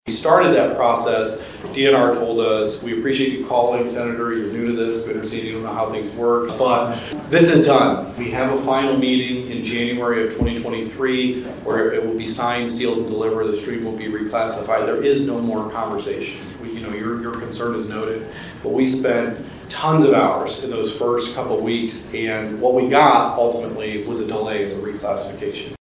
The Sturgis Area Chamber of Commerce and the Three Rivers Area Chamber of Commerce hosted a Legislative Breakfast on April 11.